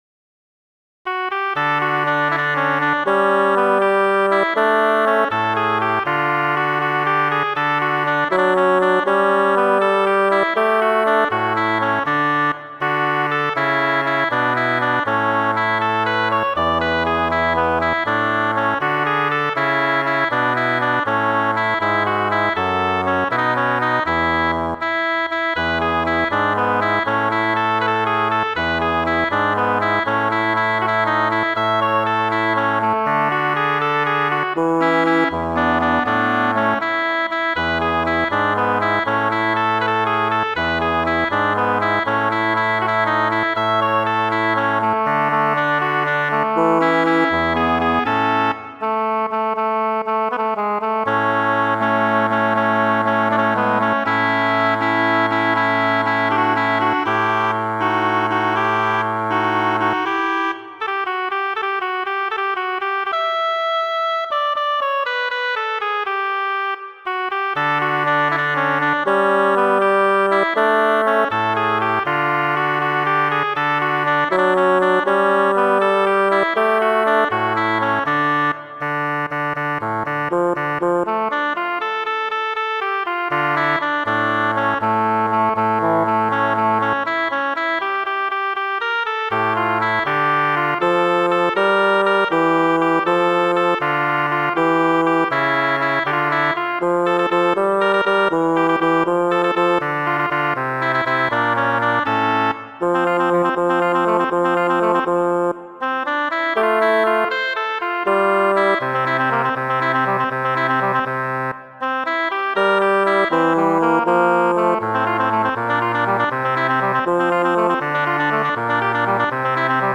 mia favorita, la rondo en re (d) majora, kiu estas mokema sed bela; kaj la lasta en la serio moka estas opus 51, A la bonne heure!